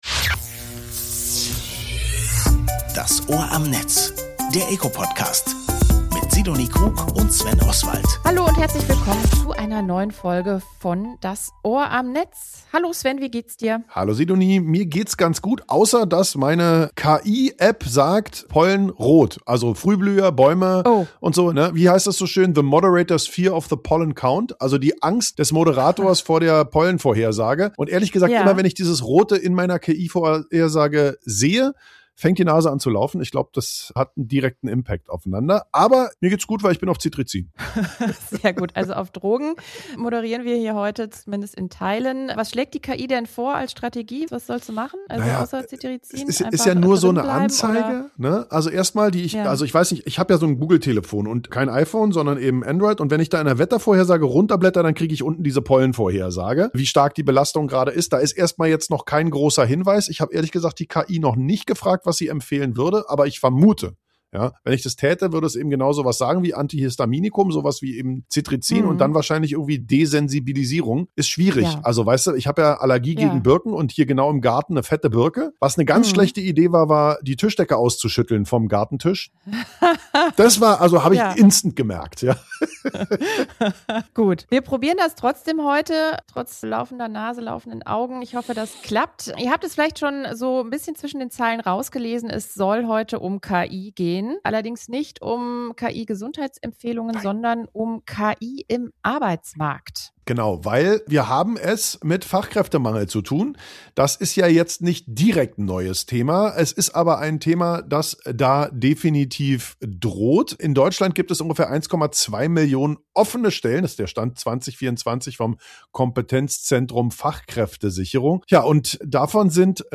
Podcast Das Ohr am Netz: Alle 14 Tage sprechen wir im eco Podcast mit spannenden Expertinnen und Experten der digitalen Welt.